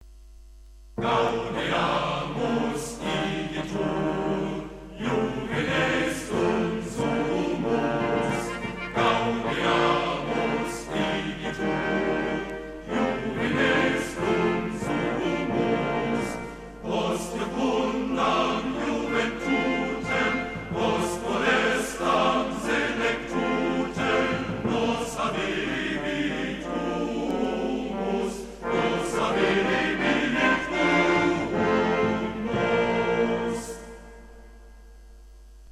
gesungen von der Gruppe